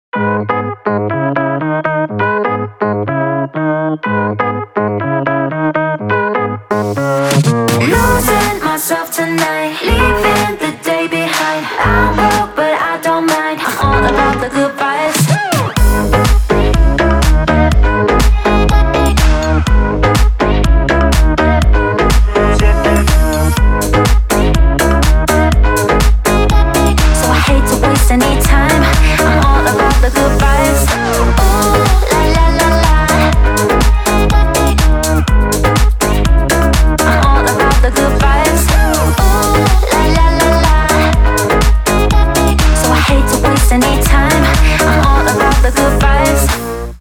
позитивные
ритмичные
заводные
dance
house
озорные
игривые